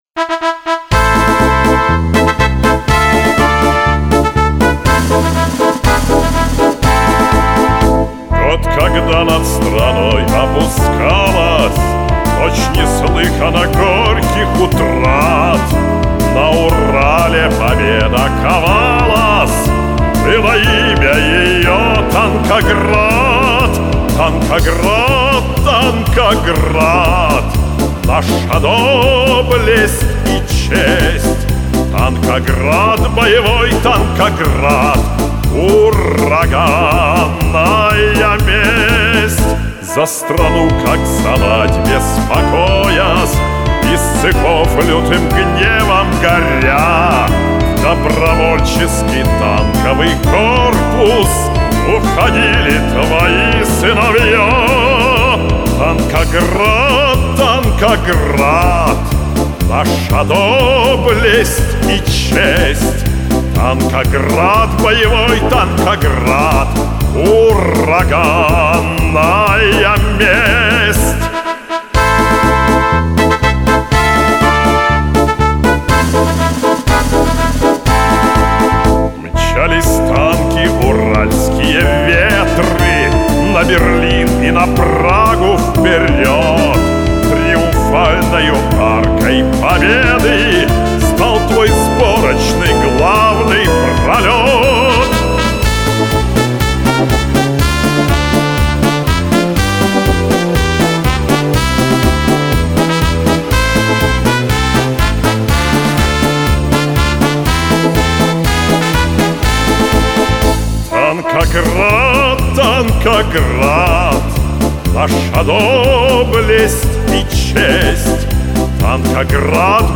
заслуженный артист России Исполнение 2010г.